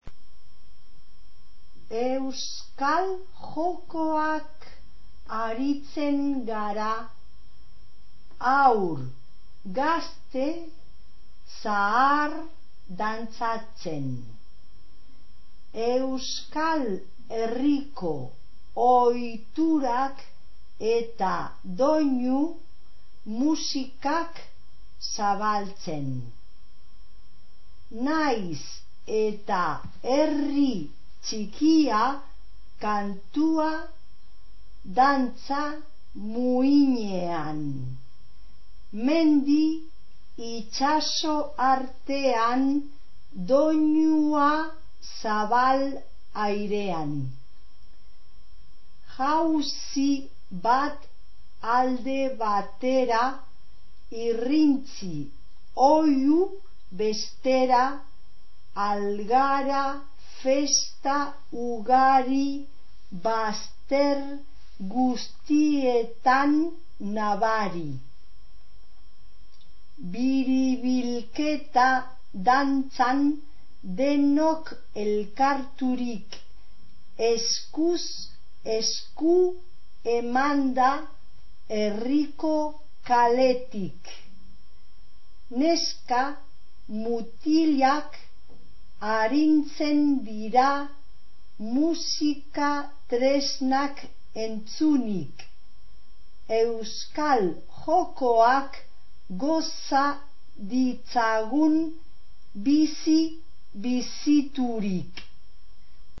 Euskal-jokoak-TEXTO-leido.mp3